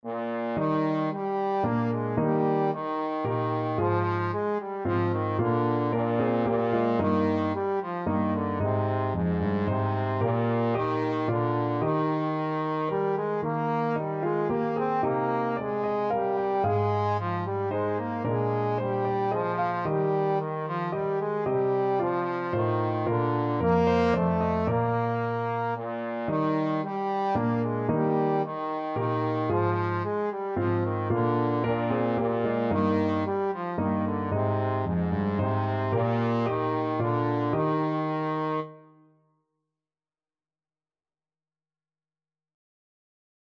Trombone
Eb major (Sounding Pitch) (View more Eb major Music for Trombone )
Moderately Fast ( = c. 112)
Ab3-C5
3/4 (View more 3/4 Music)
Classical (View more Classical Trombone Music)